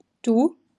IPA/duː/